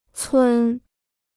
村 (cūn): village.